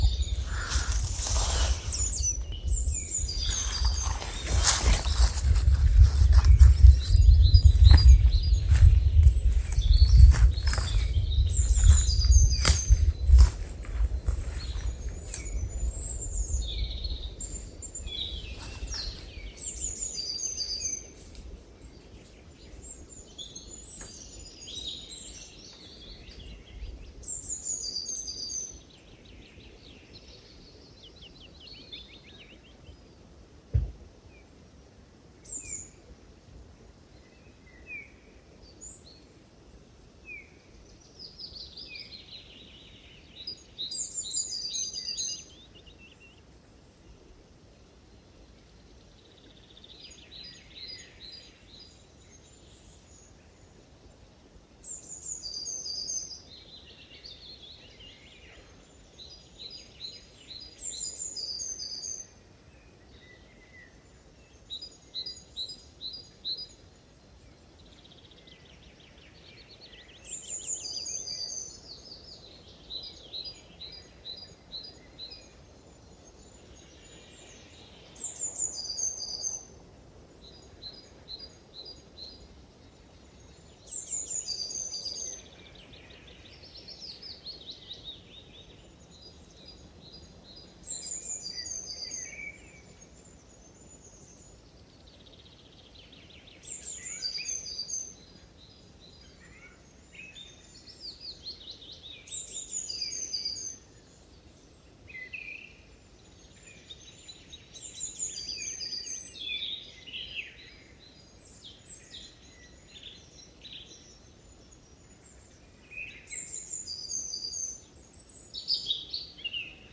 Listen to the sounds of the Swedish forests, while working, sleeping or meditating.
I was annoyed at that I couldn't find any truly pure, and authentic ambient sounds.
I recorded these in my backyard at my summer house, 1st May 2025. They turned out way better than I had hoped to.